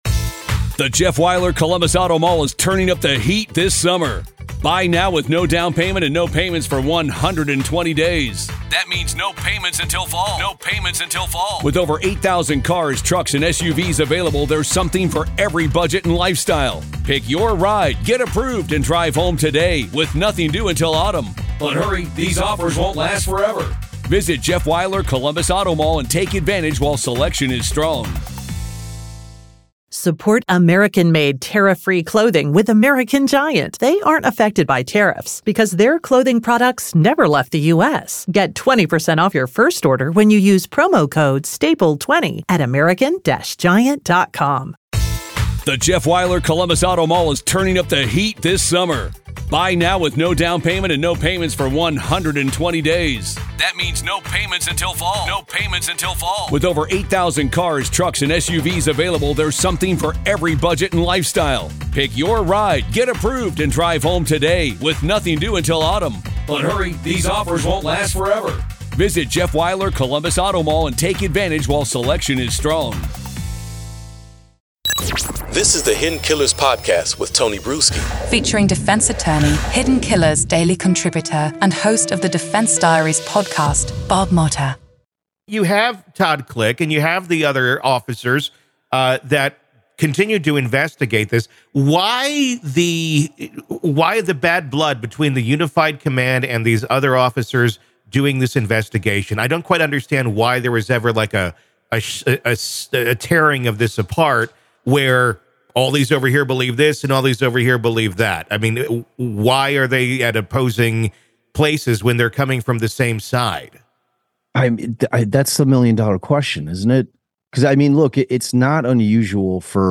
This conversation not only delves into the specifics of the Delphi case but also prompts a broader reflection on the complexities and challenges within criminal investigations.